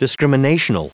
Prononciation du mot discriminational en anglais (fichier audio)
Prononciation du mot : discriminational